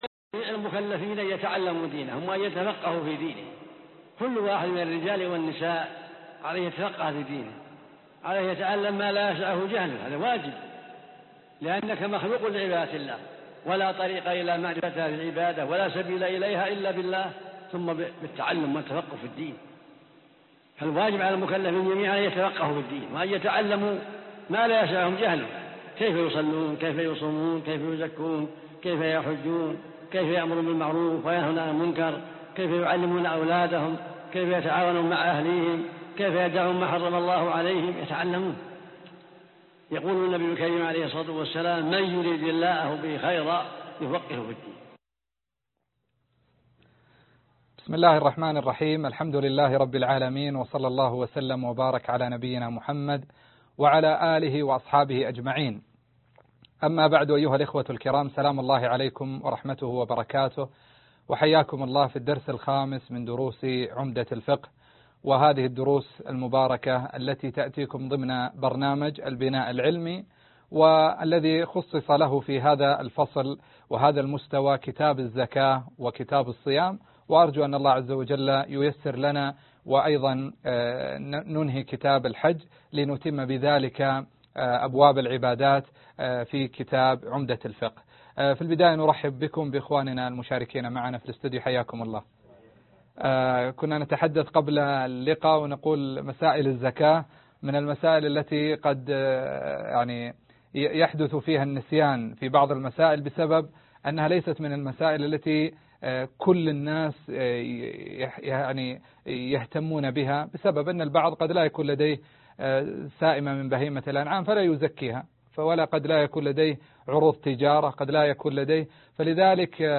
الدرس 5 كتاب الزكاة من كتاب عمدة الفقة5 (البناء العلمى المستوى الثاني)